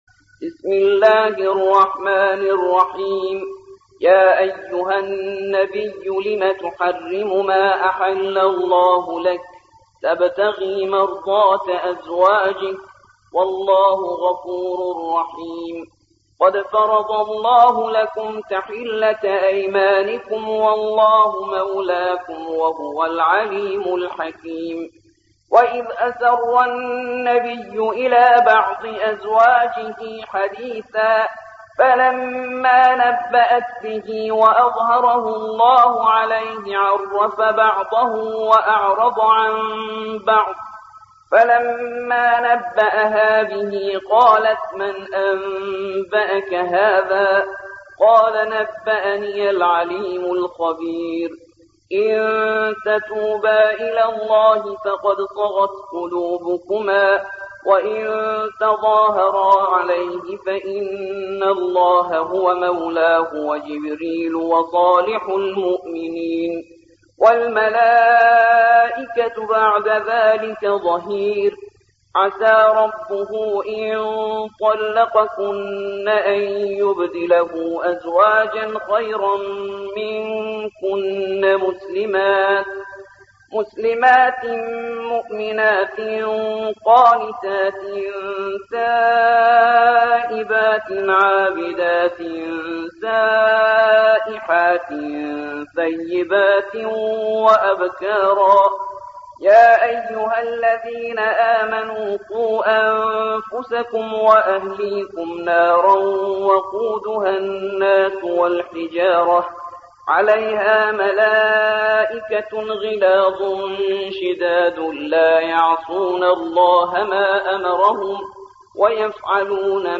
66. سورة التحريم / القارئ